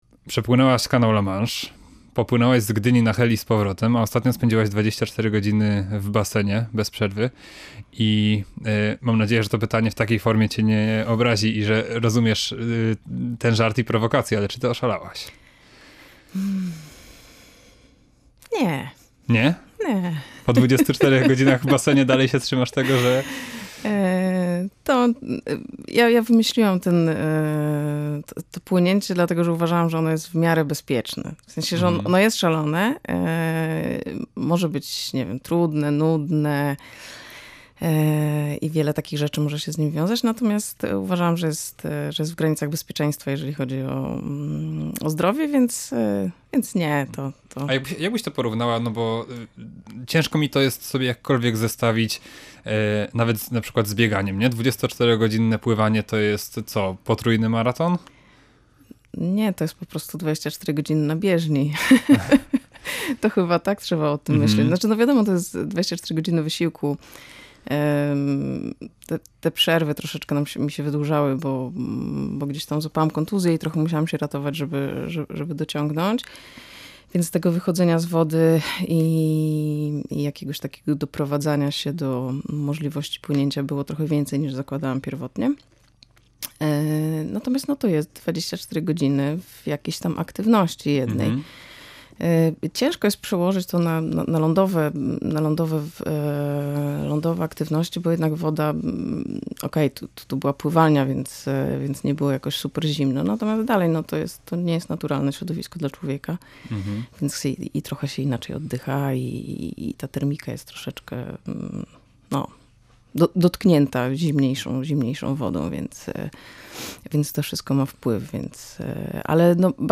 O tej wielkiej miłości i pasji do pływania, o trudach związanych z wielkimi wyczynami, o tym, że po wielkim sukcesie sportowcowi jest po prostu źle, porozmawialiśmy w audycji „Jestem z Pomorza”.